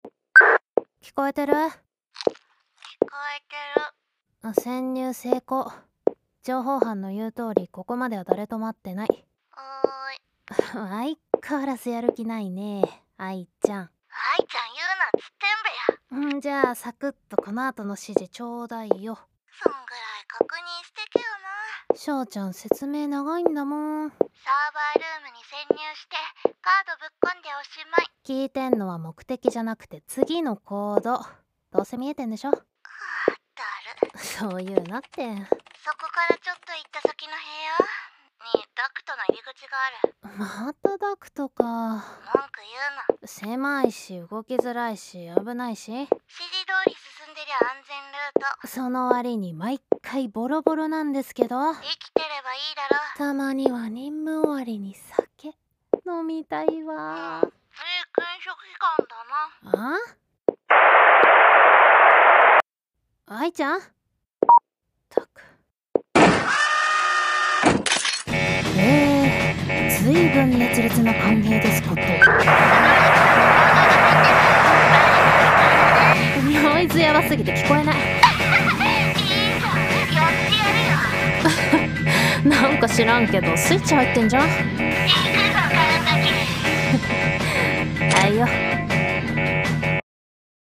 【声劇】捜査員とオペレーター